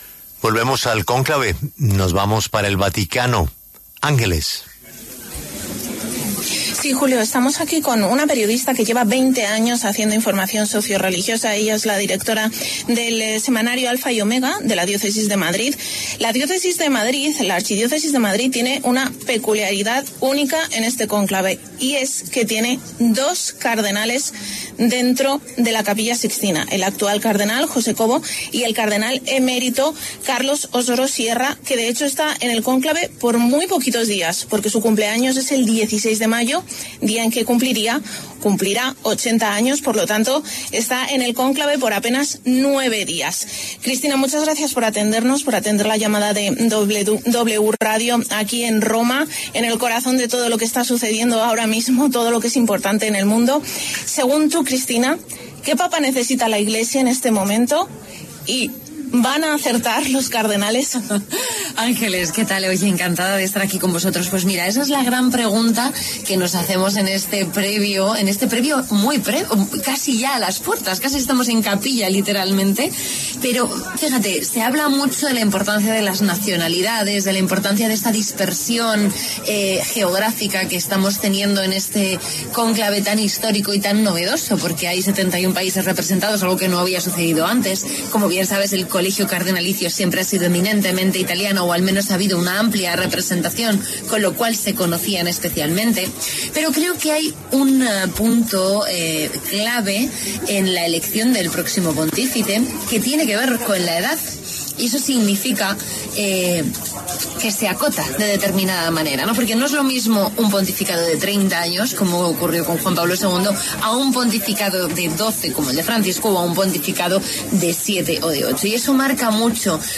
En conversación con La W